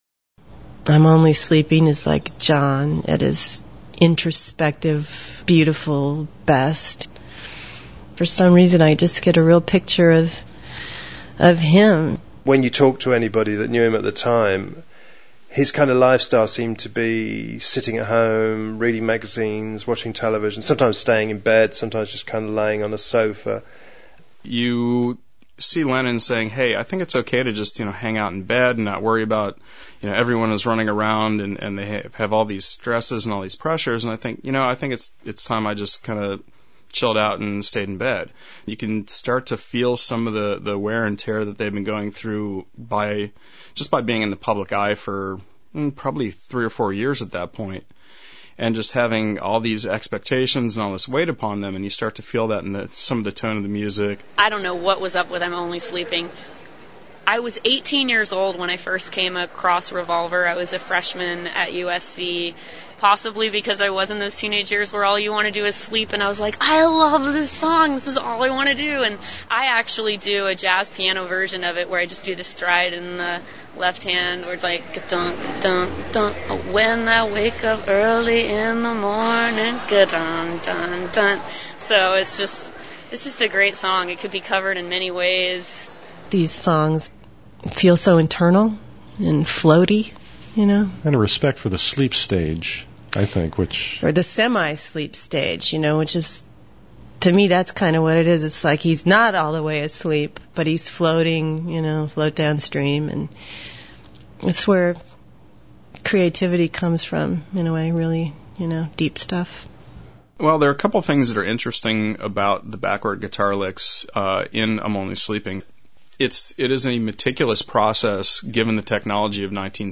HEAR COMMENTS FROM OUR GUESTS ABOUT THE SONGS OF REVOLVER: